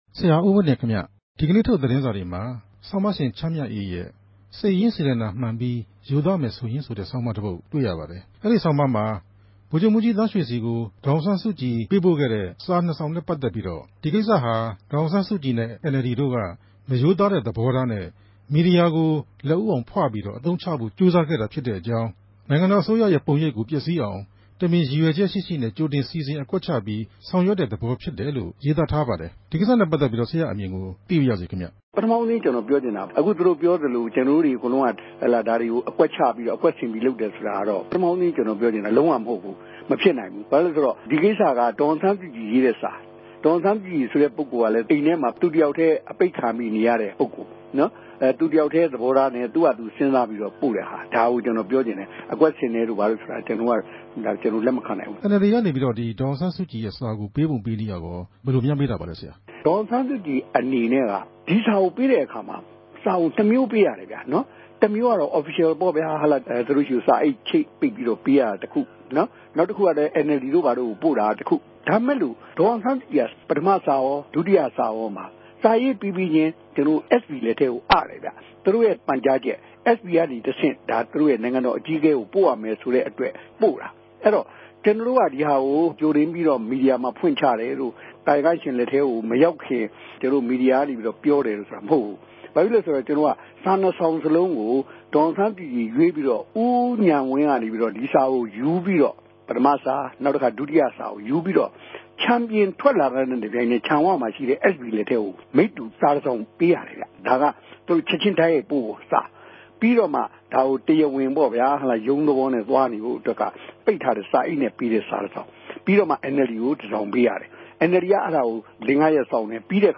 ဒီဆောင်းပၝးနဲႛပတ်သက်္ဘပီး အမဵိြးသား ဒီမိုကရေစီအဖြဲႚခဵြပ် အလုပ်အမြဆောင်အဖြဲႚဝင် သတင်းစာဆရာဋ္ဌကီး ဦးဝင်းတင်က ခုလို ေူပာပၝတယ်။
ဆက်သြယ်မေးူမန်းခဵက်။